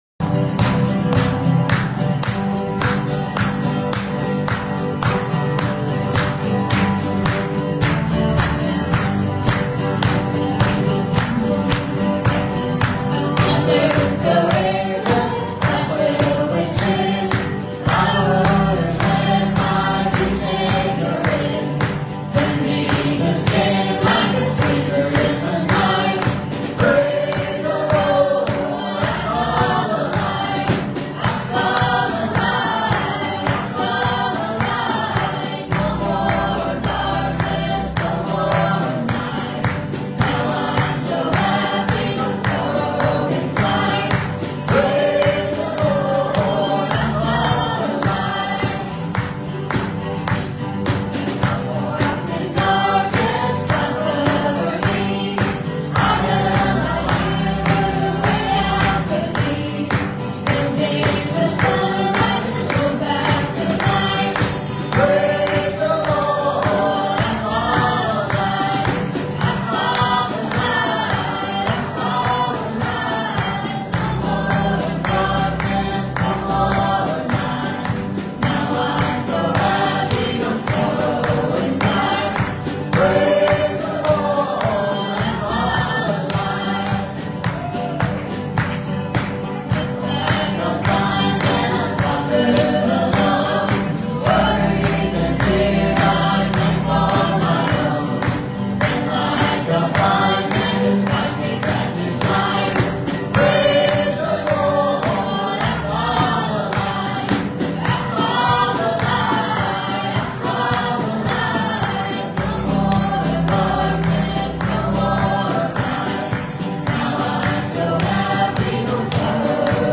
Passage: Romans 6:1-10 Service Type: Sunday Morning